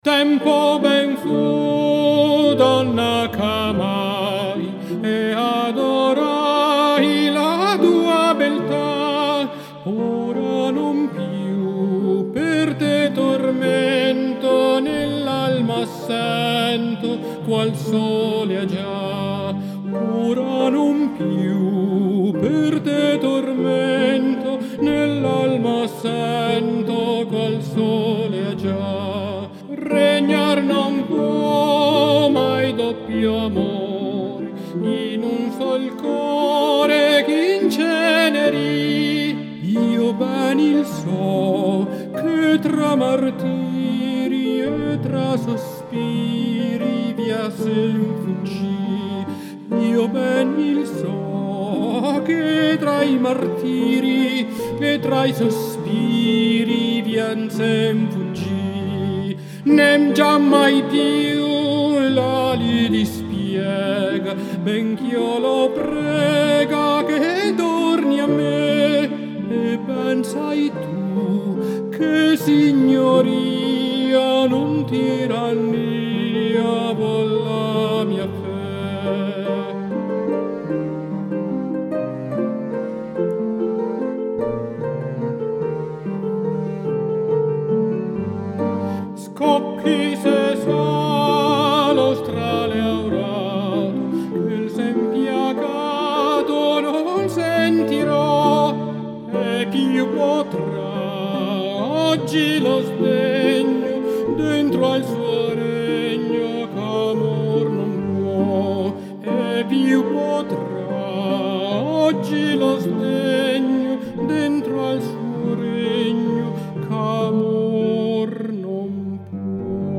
e una canzonetta barocca